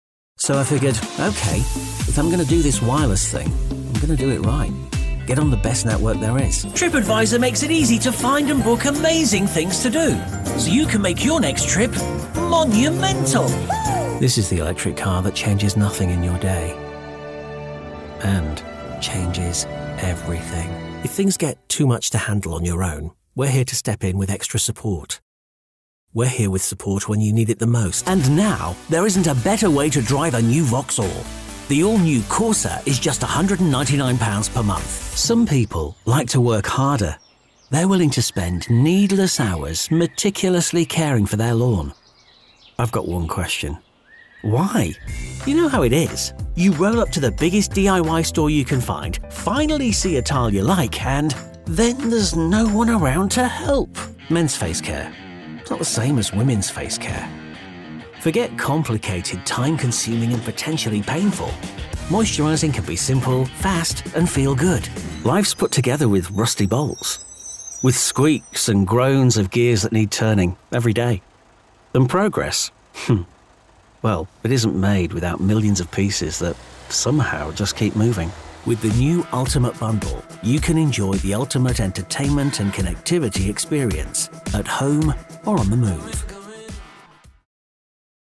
British English Adult Male Voice.
Warm, friendly, conversational, ageless, engaging, authoritative, playful, authentic, genuine, smooth, knowledgeable, relaxed.
Sprechprobe: Werbung (Muttersprache):
I am a UK based, native English accent voiceover, with my own broadcast studio.
Studio: Neumann TLM193 mic / Audient id4 & Focusrite Scarlett 2i2 interfaces / Adobe Audition (CC 2020) Connections: ipDTL, ISDN, Source Connect Now, Cleanfeed.